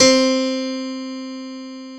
Keys (1).wav